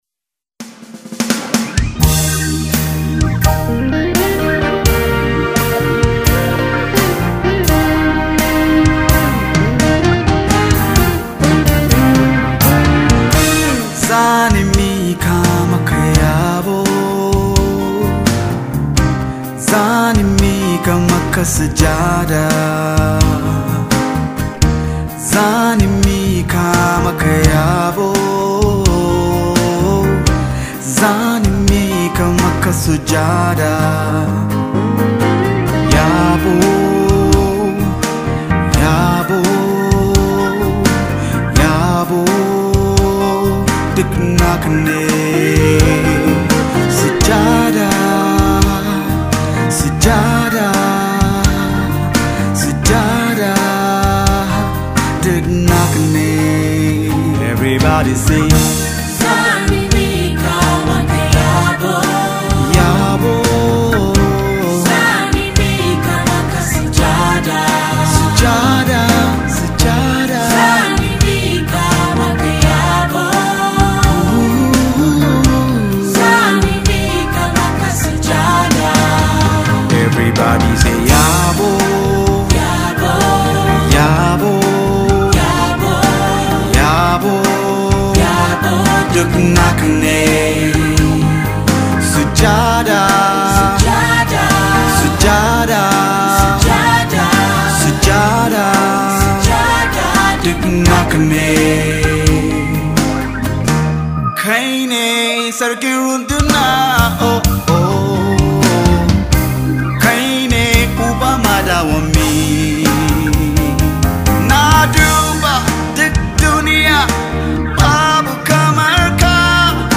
AlbumsMUSICNaija Gospel Songs